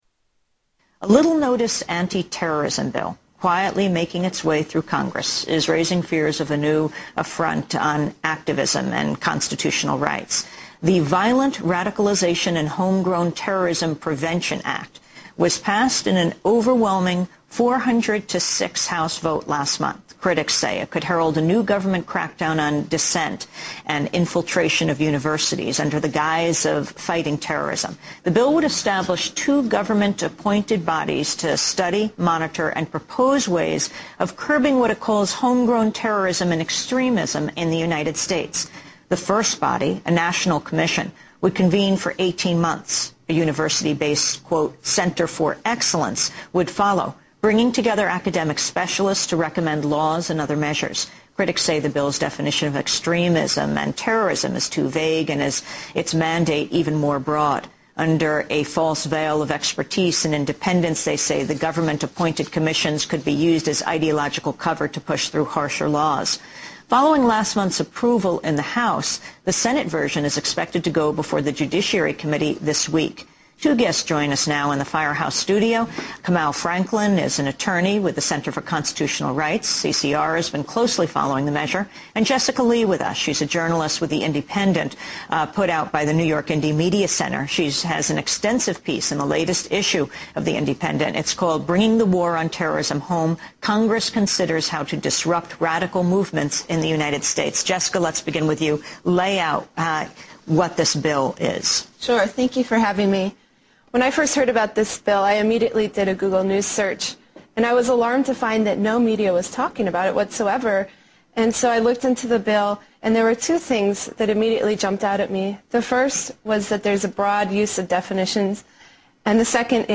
Tags: Historical Top 10 Censored News Stories 2009 Censored News Media News Report